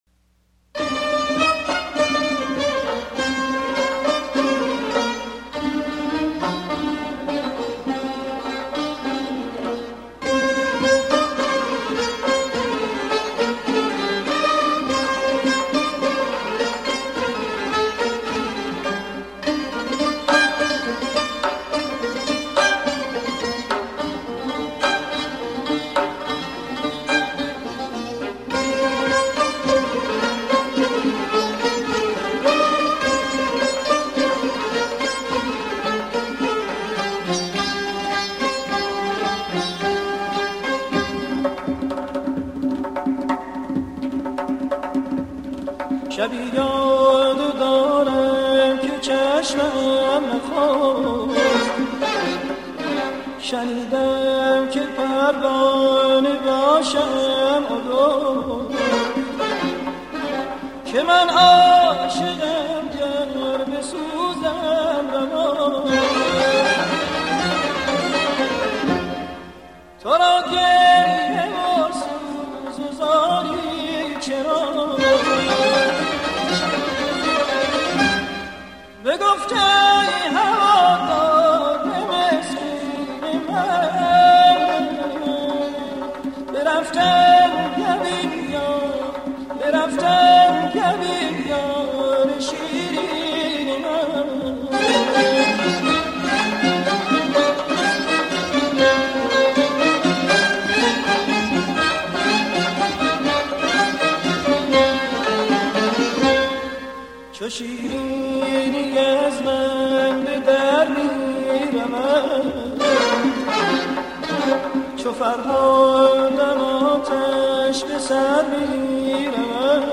دستگاه: شور